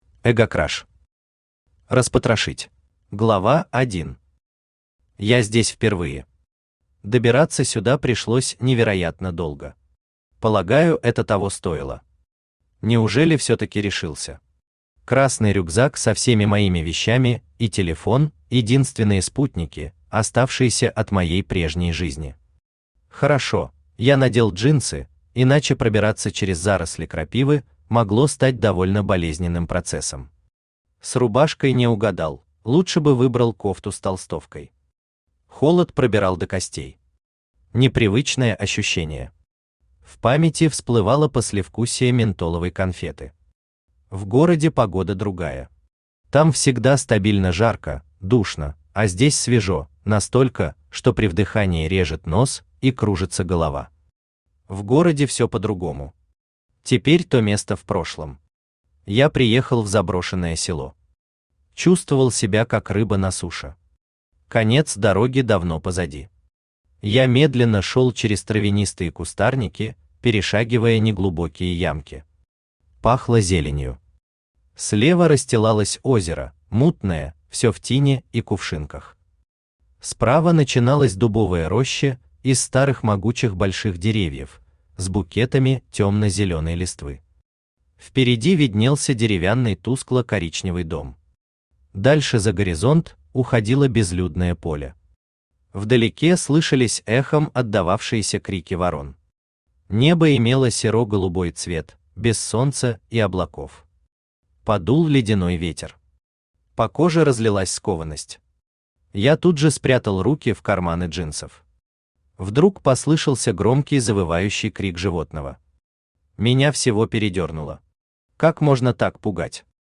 Читает: Авточтец ЛитРес
Аудиокнига «Распотрошить».